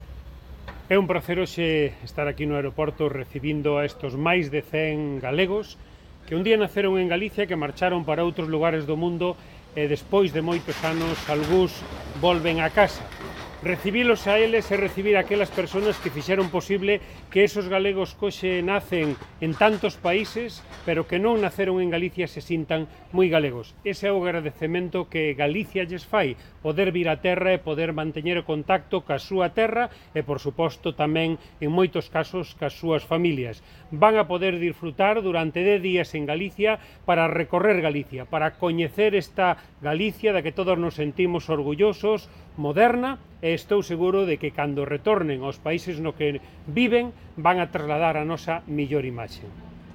Declaracións do secretario xeral da Emigración